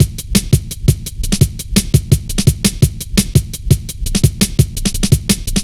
Index of /90_sSampleCDs/Zero-G - Total Drum Bass/Drumloops - 3/track 61 (170bpm)